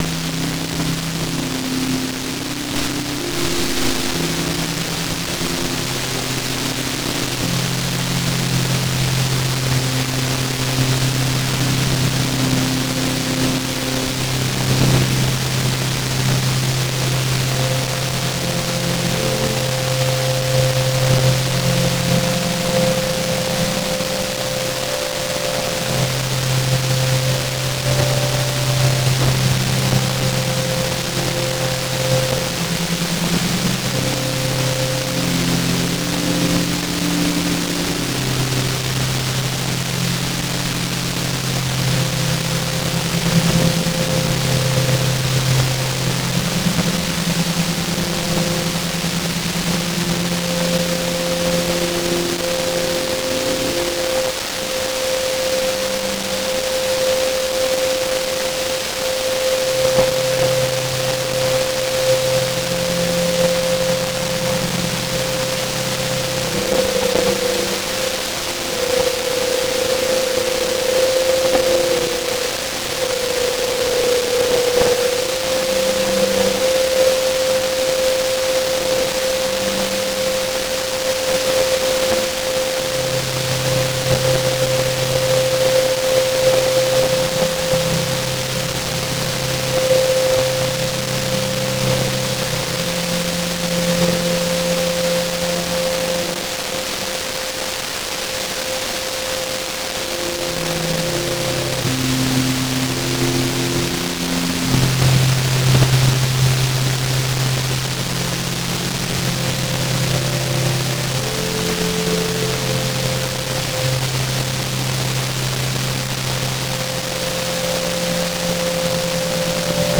②歪ませてノイジーにする
※音量注意
輪郭は曖昧になり、崩れ落ちそうなメロディが暖かみのあるノイズに包まれています。